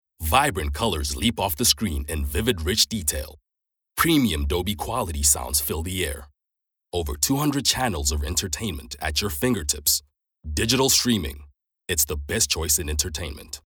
authentic, authoritative, captivating, confident, dramatic, energetic, informative
Digital Product Sale (English US)